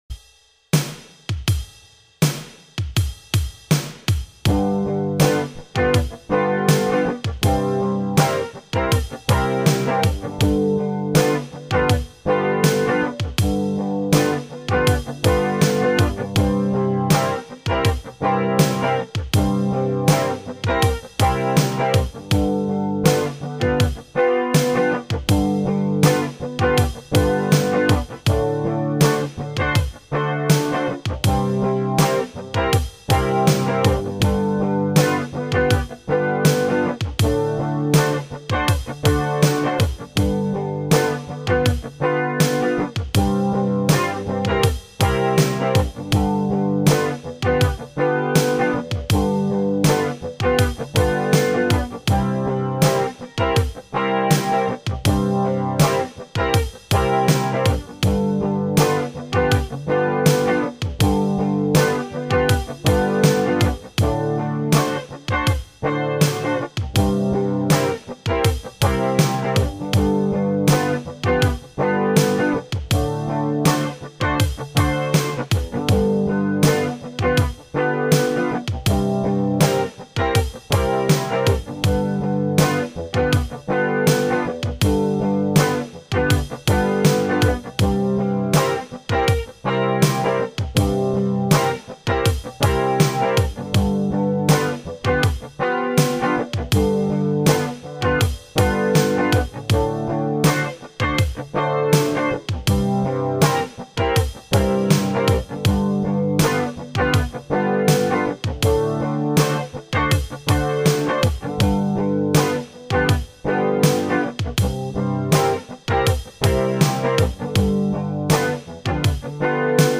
12 bar blues type progression using the chords G7, C7 and D7.